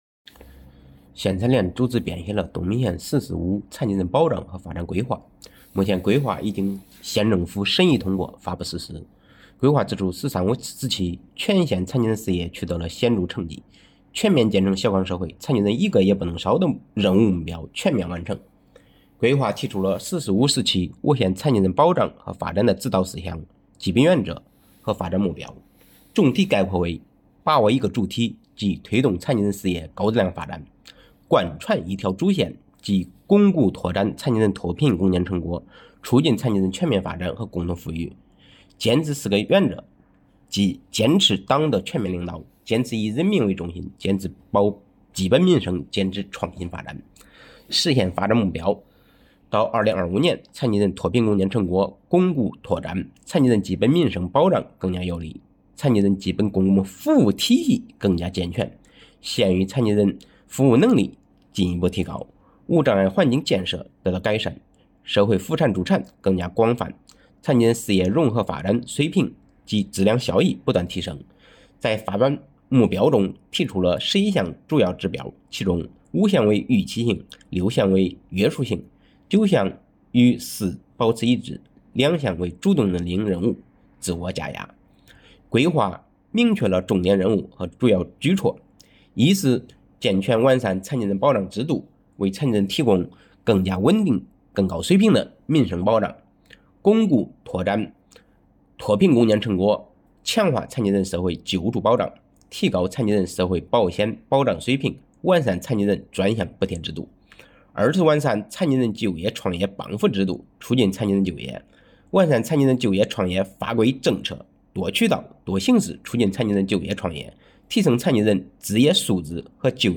• 分　　类：音频解读